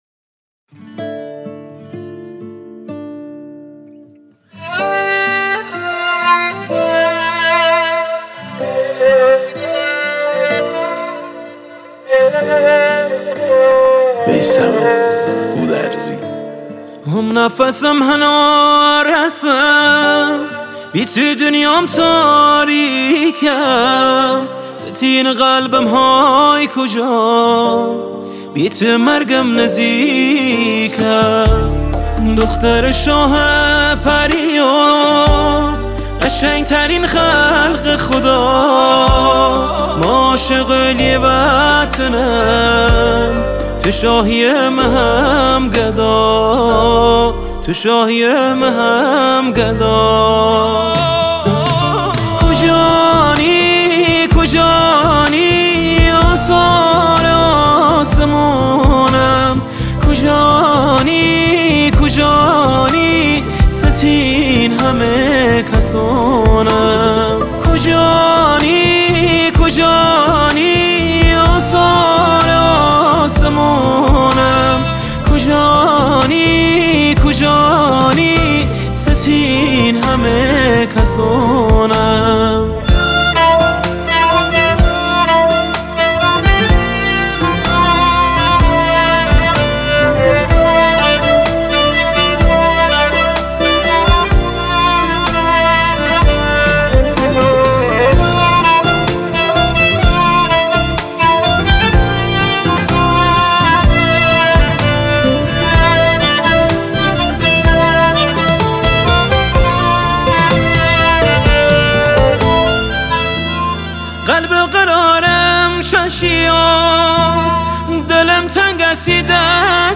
آهنگ لری و لکی